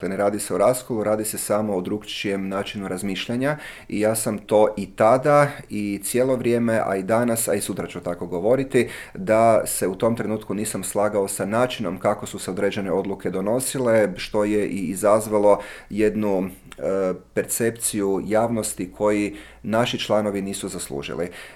ZAGREB - Međimurski župan Matija Posavec, nositelj HNS-ove liste za europske izbore, u razgovoru za Media servis otkrio je zašto ustraje na samostalnom izlasku, predstavlja li HNS-u vodstvo uteg, planira li zasjesti na mjesto predsjednika stranke i zašto nije bio za ulazak u vladajuću koaliciju.